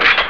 File: "shotgun loads" (fucile che viene caricato)
Type: Sound Effect